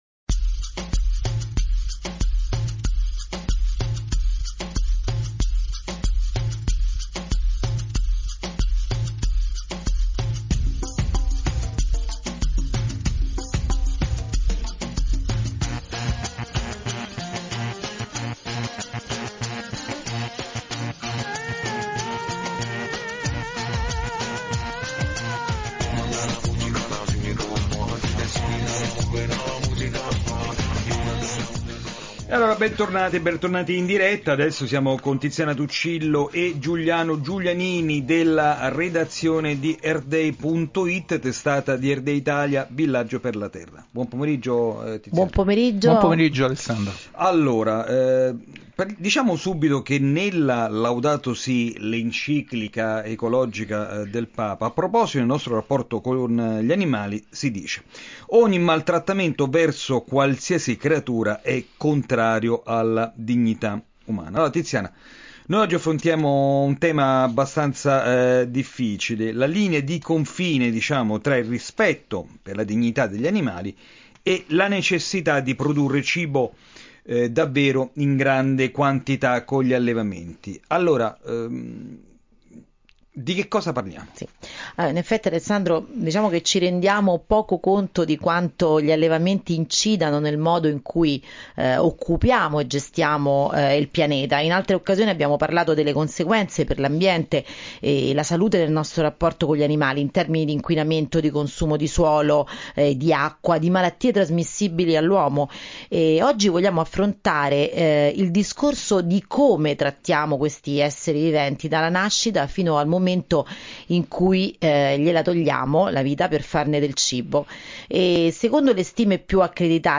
la rubrica radiofonica settimanale curata da Earth Day Italia, trasmessa da Radio Vaticana Italia